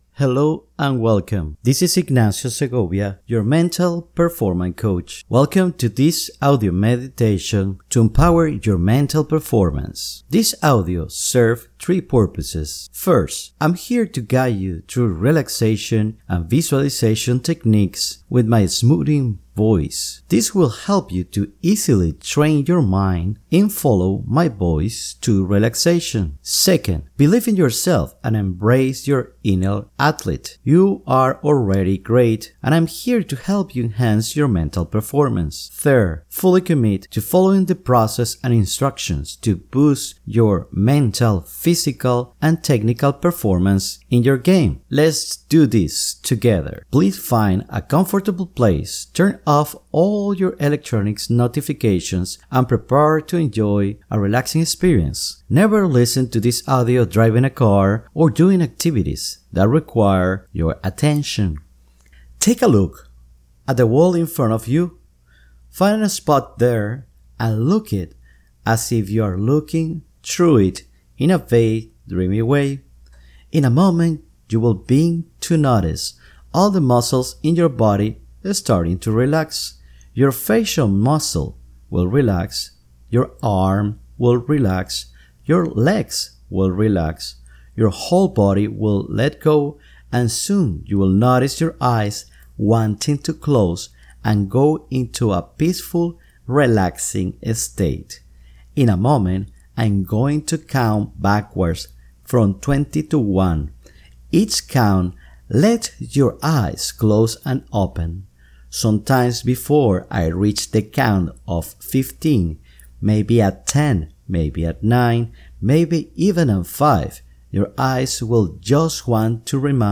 This is an excellent introduction to the world of hypnosis, offering a gentle experience that serves as your first step into our upcoming hypnosis sessions.